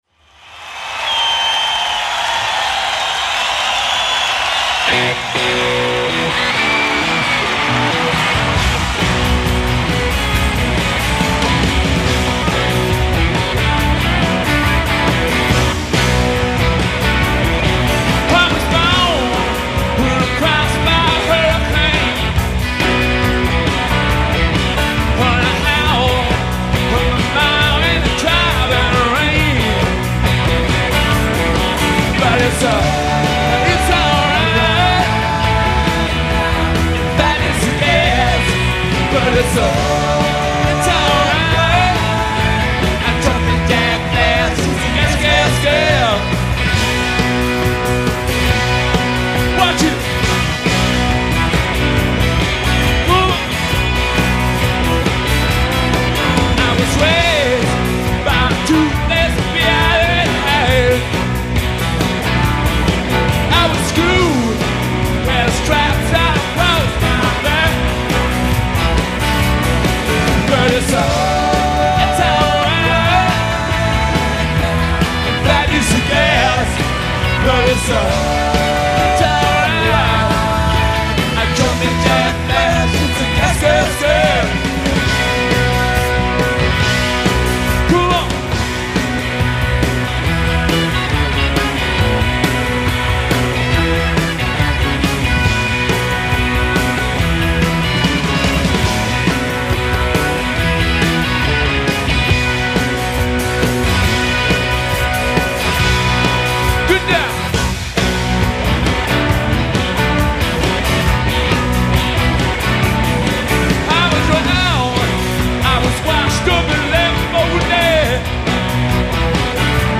live radio broadcast